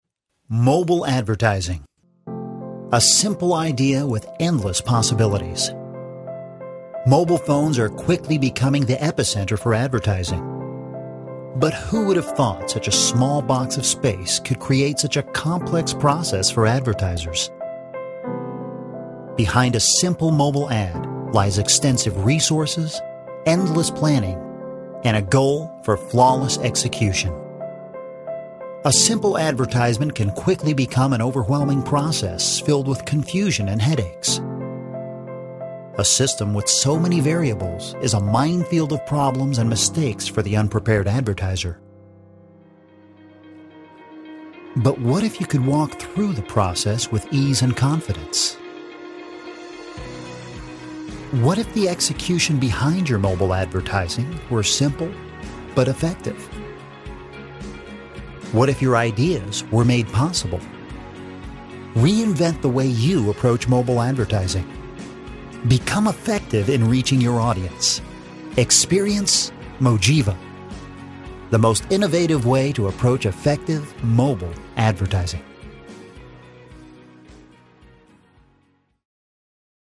A versatile Voice Actor who takes direction well and can also deliver a variety of unique character voices.
Conversational, guy next door, humorous, enthusiastic, character, youthful, caring.
Sprechprobe: Industrie (Muttersprache):